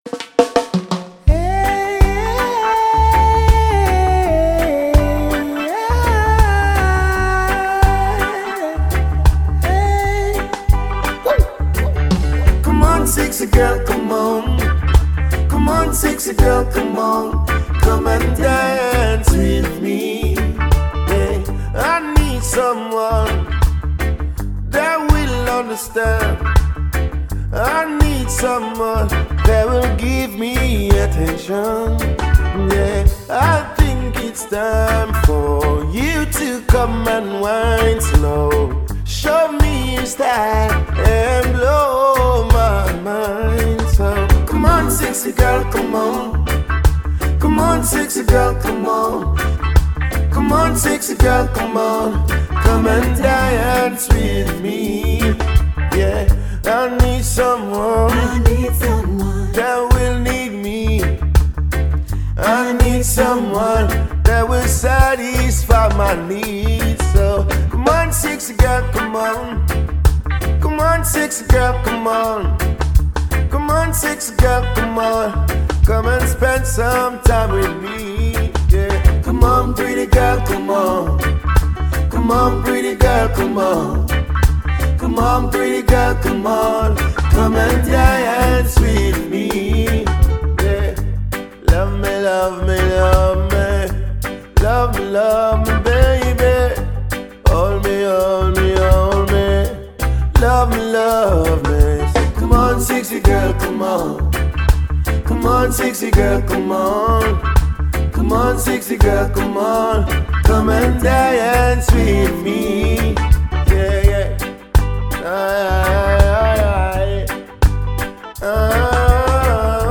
Dancehall/HiphopMusic
Jamaican toppest dancehall act